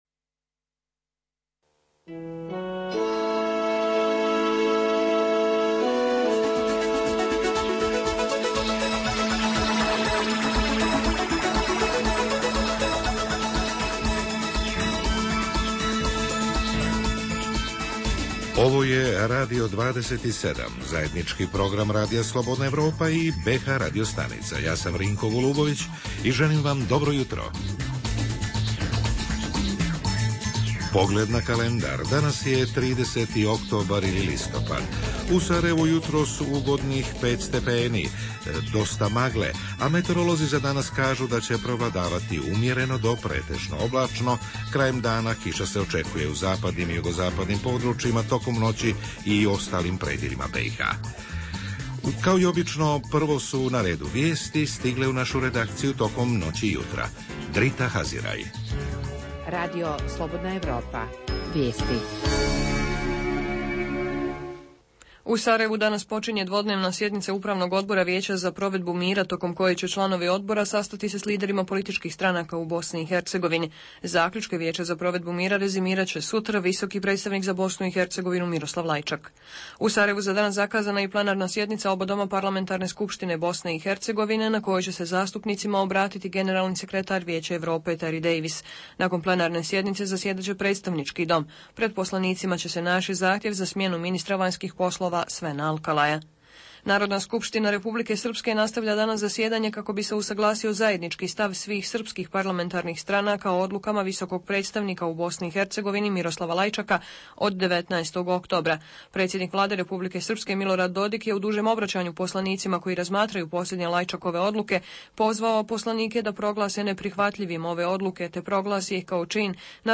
Prilozi i ankete reportera Radija 27 iz Sarajeva, Prijedora i Doboja.
Redovni sadržaji jutarnjeg programa za BiH su i vijesti i muzika.